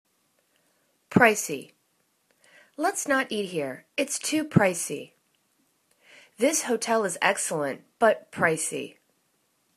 pric.ey    /'pri:si/    adj